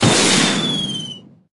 rocket_rose_atk_02.ogg